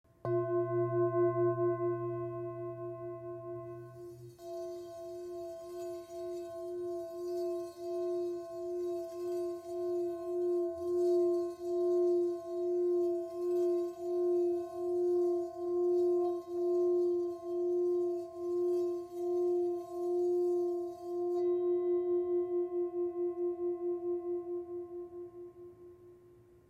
Tibetská mísa Chuto velká
tibetska_misa_v14.mp3